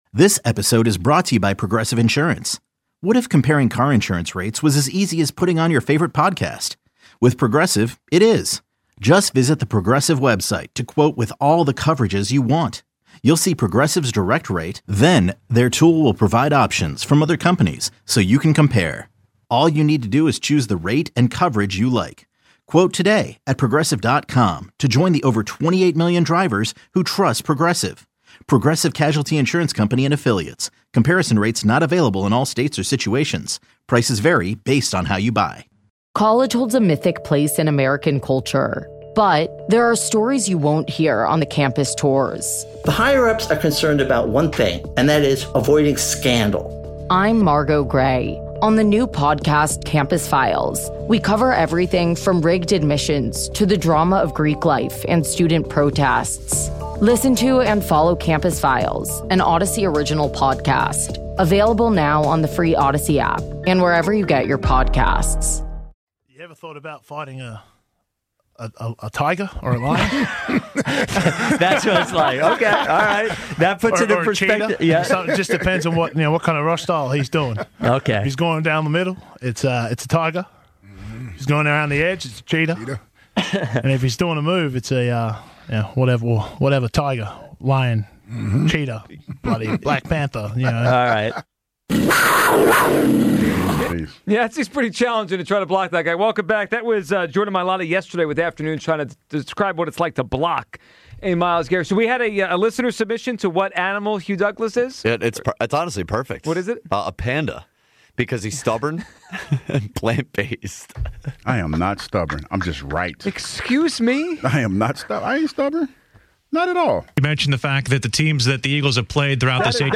Best of WIP Interviews: Eagles Divisional Round
Hear from Donovan McNabb, Brandon Graham, Nick Sirianni, Ross Tucker, Jordan Mailata, and Jason Kelce following the Eagles’ win over the Packers and ahead of the upcoming matchup against the Rams.